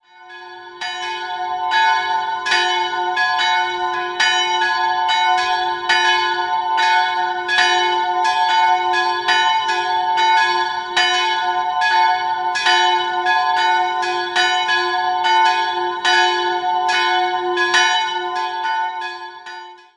Im Inneren erwarten den Besucher drei hübsche Barockaltäre. 2-stimmiges Geläute: fis''-gis'' Die größere Glocke wurde 1451 vermutlich in der Nürnberger Gießhütte gegossen, die kleinere ist schmucklos und unbezeichnet.